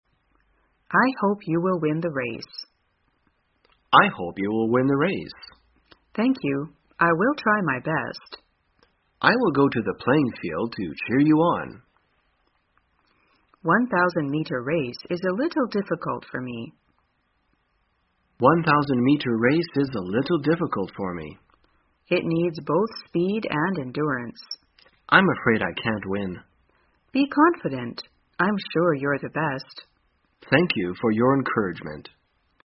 在线英语听力室生活口语天天说 第233期:怎样祝愿别人获胜的听力文件下载,《生活口语天天说》栏目将日常生活中最常用到的口语句型进行收集和重点讲解。真人发音配字幕帮助英语爱好者们练习听力并进行口语跟读。